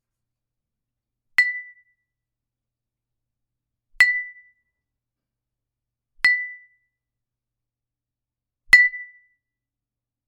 Ding 4
cheers chin chink clink cup ding glass mug sound effect free sound royalty free Sound Effects